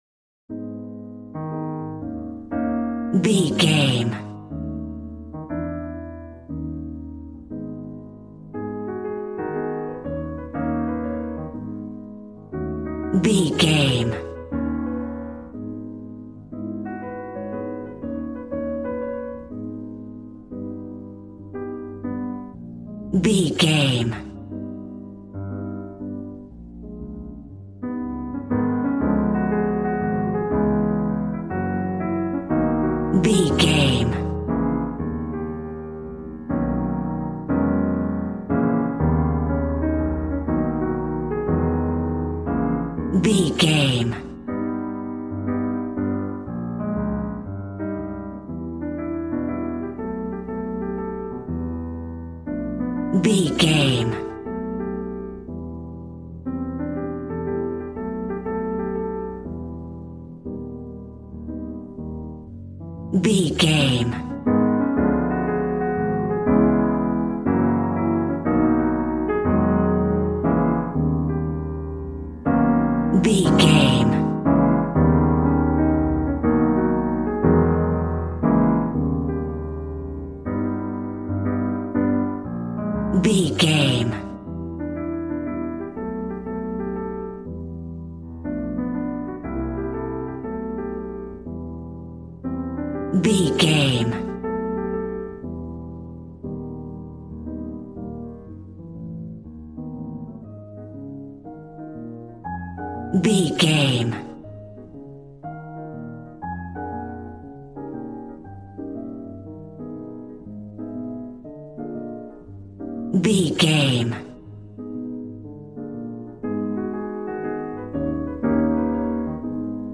Smooth jazz piano mixed with jazz bass and cool jazz drums.,
Aeolian/Minor
A♭